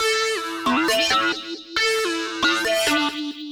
Index of /musicradar/future-rave-samples/136bpm
FR_RaveSquirrel_136-A.wav